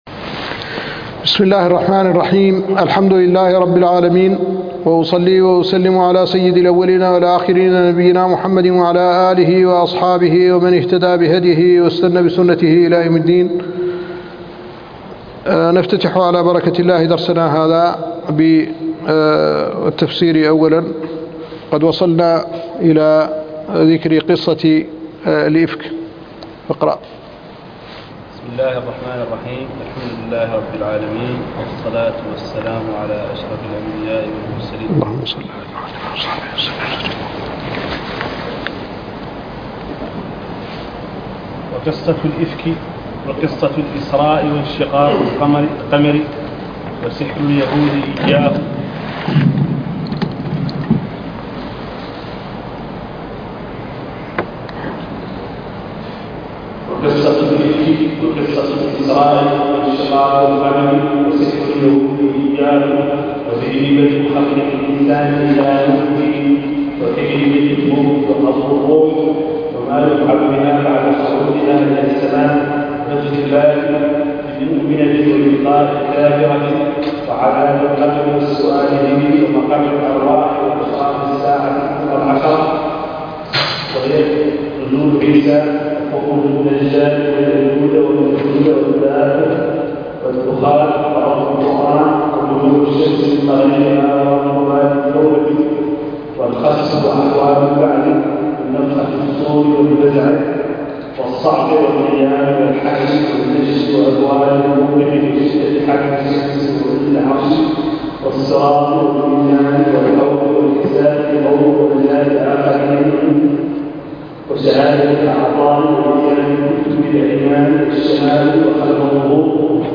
الدرس الرابع عشر